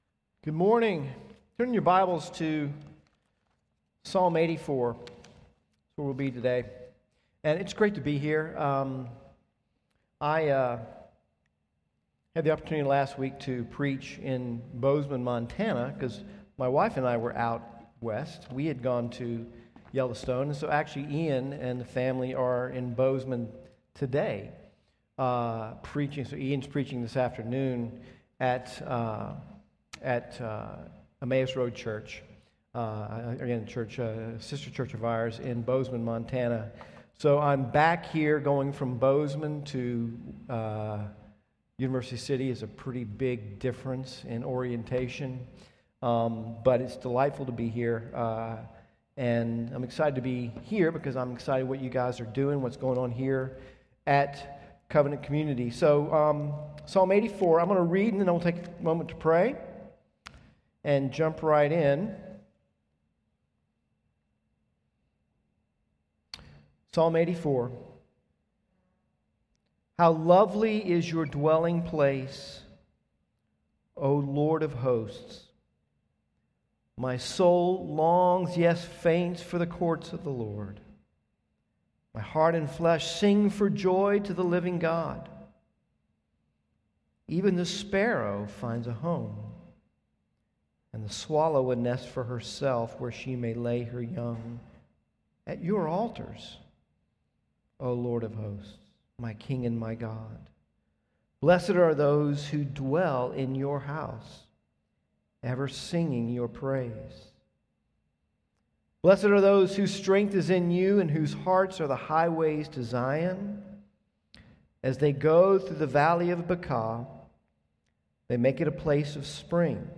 A sermon from the series "Stand Alone Sermons." Jesus came to pay the ransom to set us free from our slavery to sin. True freedom is not independence but living each day in light of the good news that we belong to God.